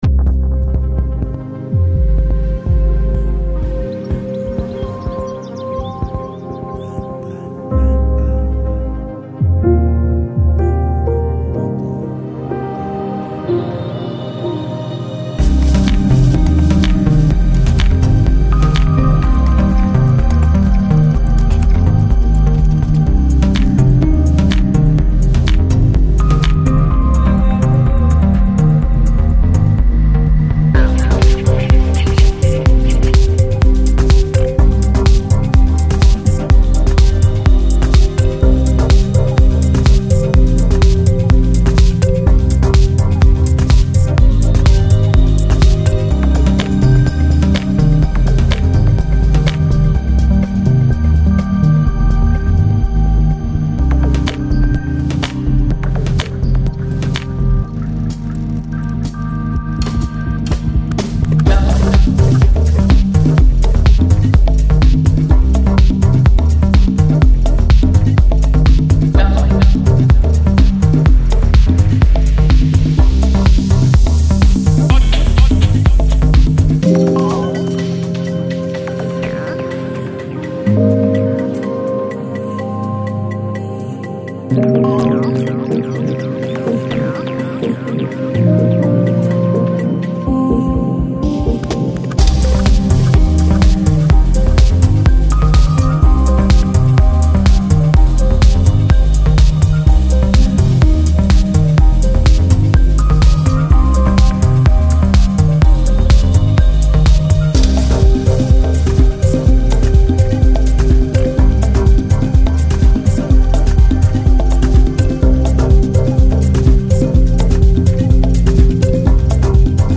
Жанр: Lounge, Chill House, Downtempo